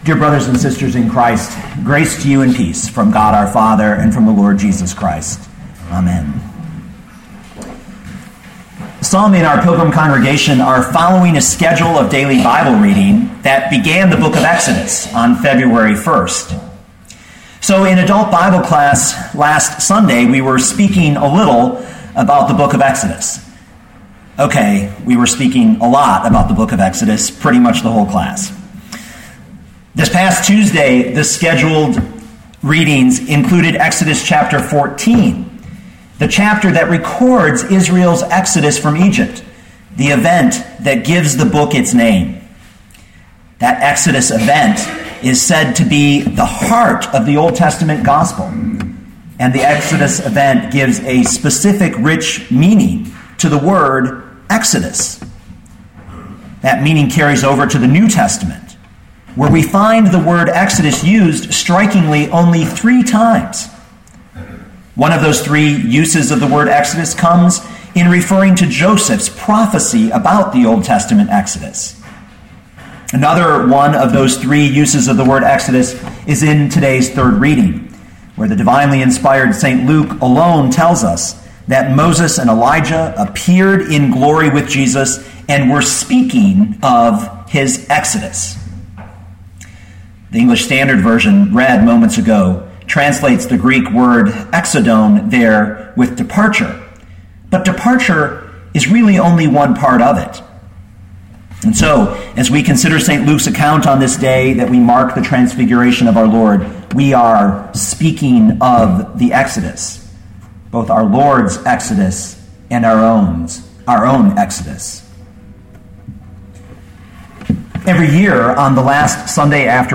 2013 Luke 9:28-36 Listen to the sermon with the player below, or, download the audio.